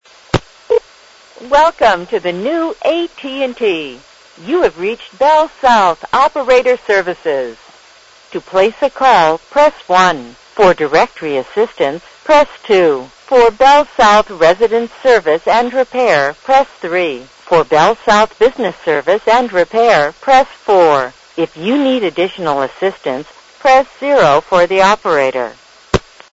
The following are examples of other local or long distance telephone company operator service platforms sounds and recordings.